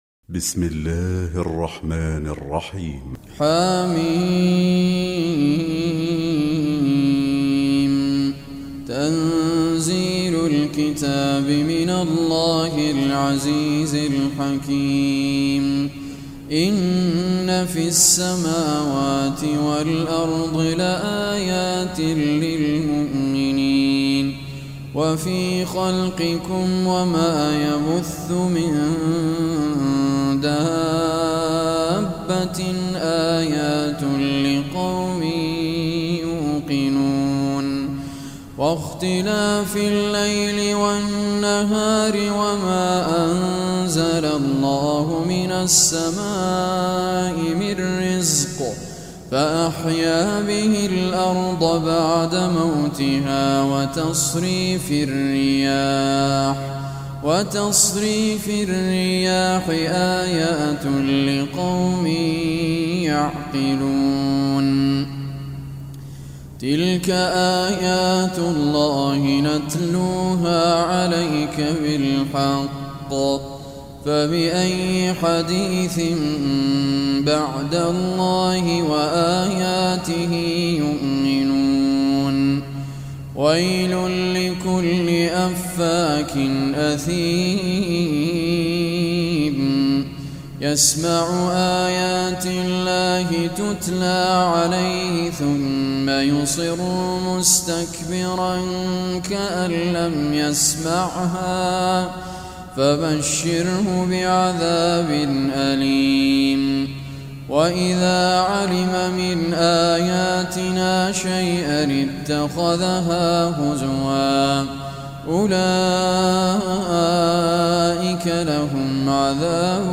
Surah Al-Jathiyah Recitation by Raad Al Kurdi
Surah Al-Jathiyah is 45 surah of Holy Quran. Listen or play online mp3 tilawat/ recitation in Arabic in the beautiful voice of Sheikh Raad Al Kurdi.